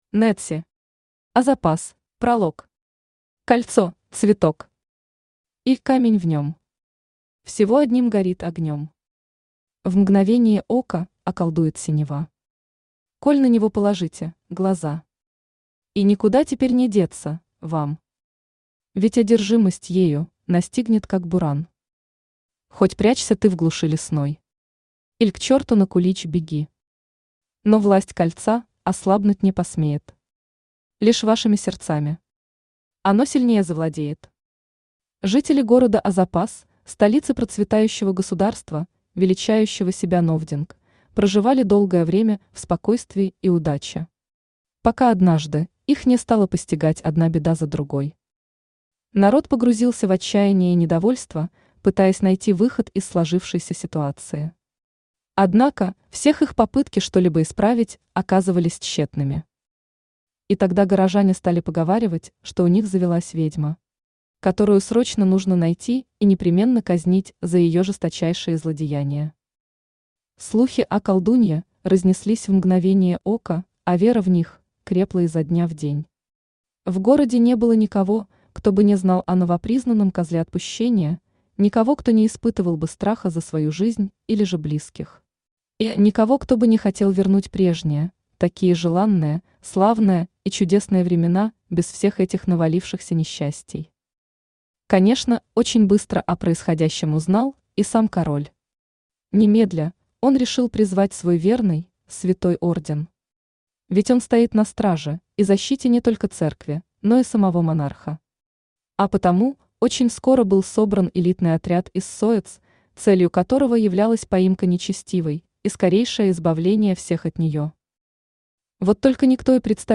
Aудиокнига Азопас Автор Нэтси Читает аудиокнигу Авточтец ЛитРес.